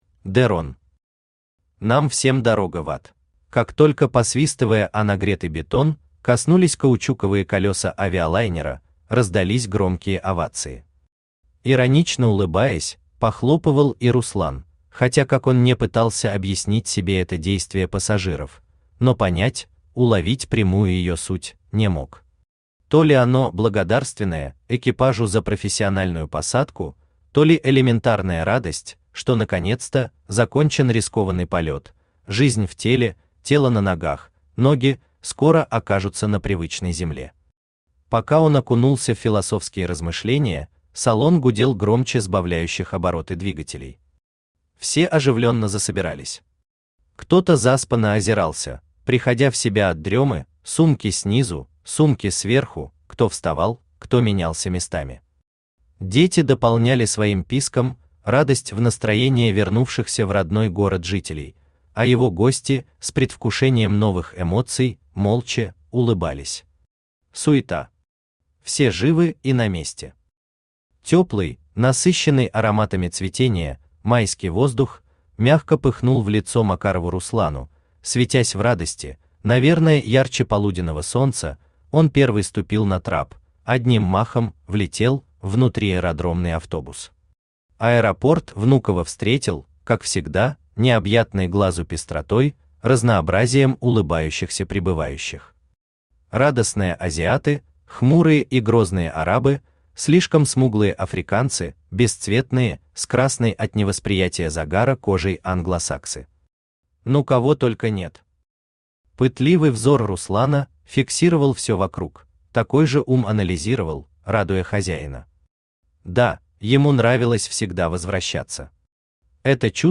Аудиокнига Нам всем дорога в АД | Библиотека аудиокниг
Aудиокнига Нам всем дорога в АД Автор De Ron Читает аудиокнигу Авточтец ЛитРес.